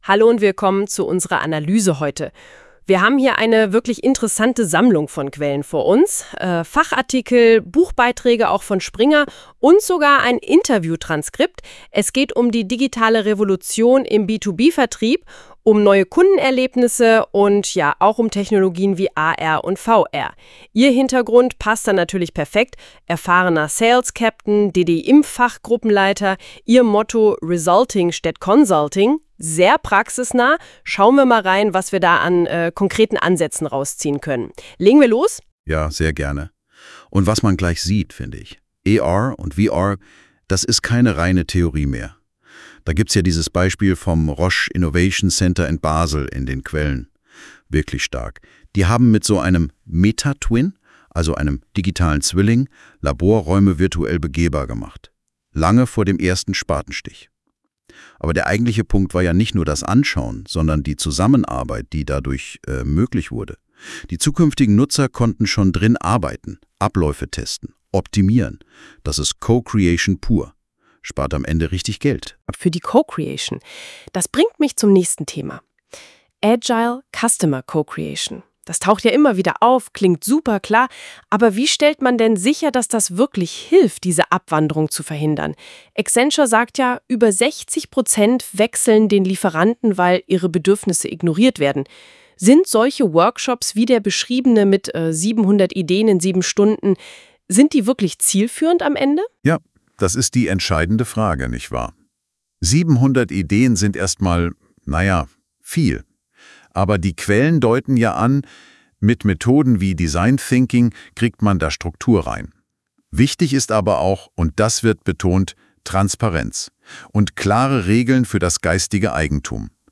Hier haben Sie die Möglichkeit, einen Podcast über meine Publikationen zu hören. Der Podcast wurde von künstlicher Intelligenz generiert.